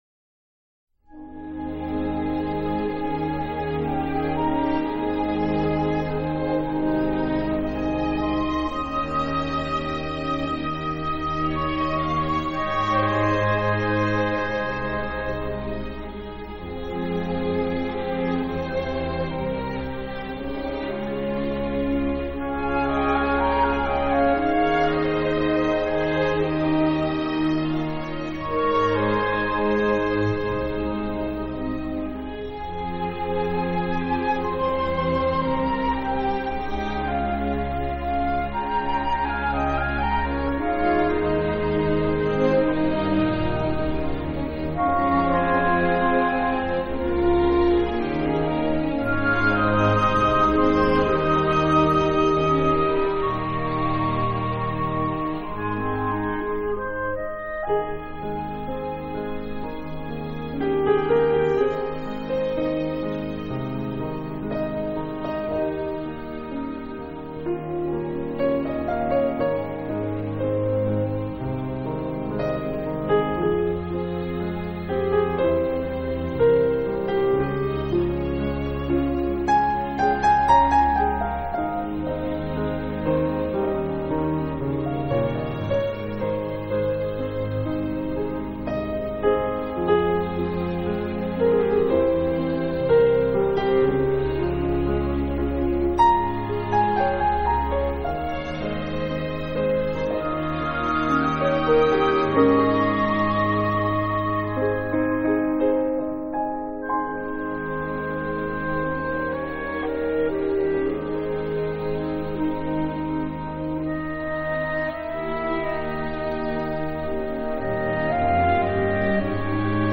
Soundtrack, Classical